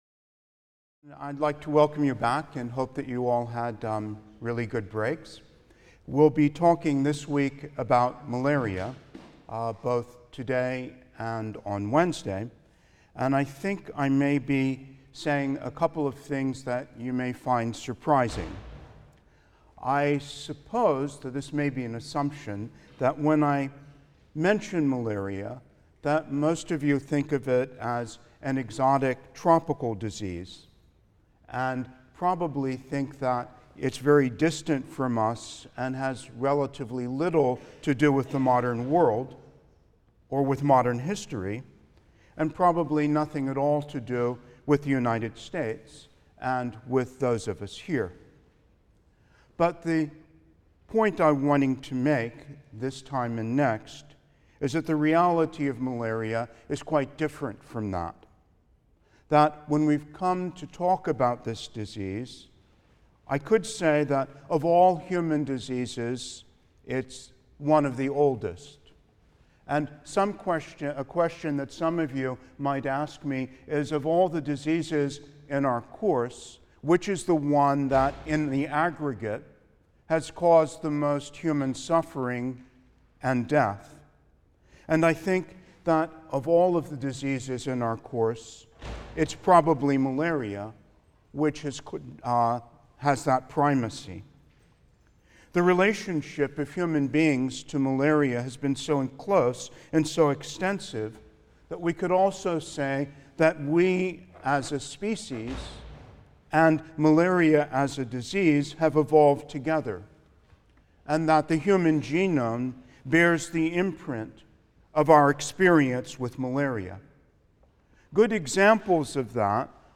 HIST 234 - Lecture 16 - Malaria (I): The Case of Italy | Open Yale Courses